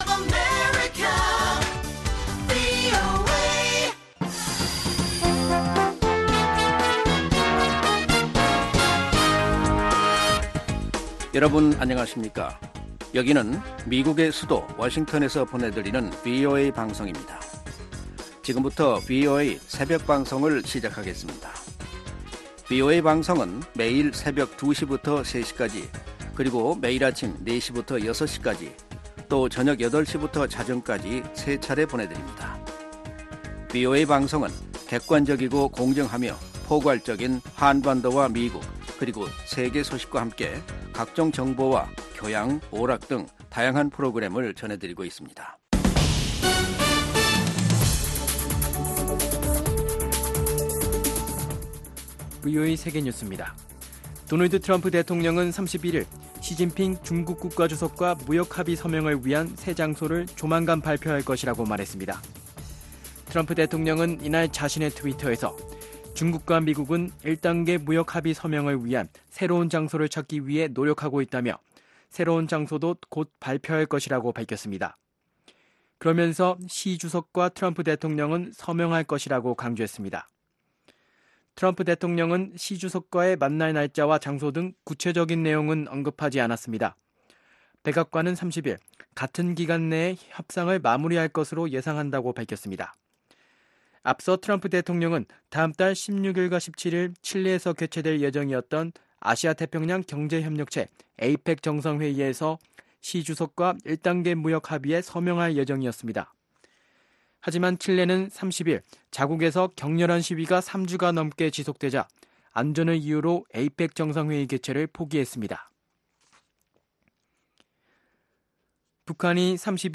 VOA 한국어 '출발 뉴스 쇼', 2019년 11월 1일 방송입니다. 북한이 오늘(31일) 오후 평안남도에서 동해 방향으로 미상의 발사체 두발을 발사했고 한국 정부가 강한 우려를 밝혔습니다.